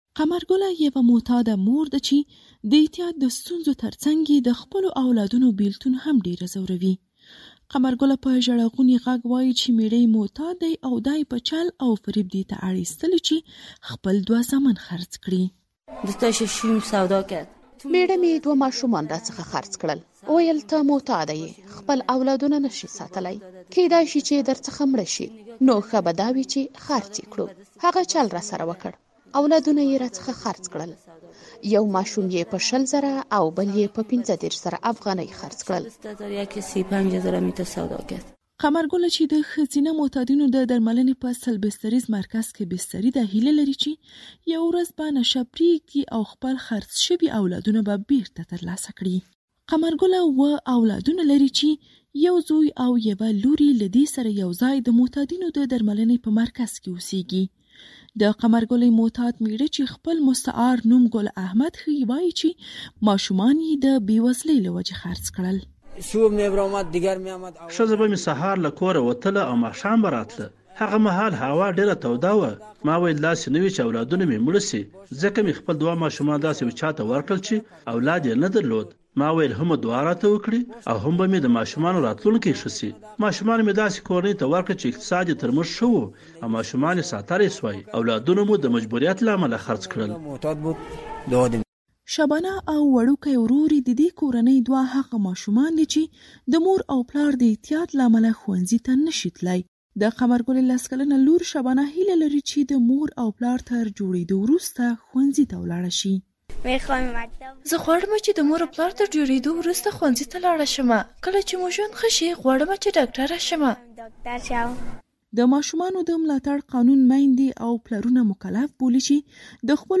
فیچر راپور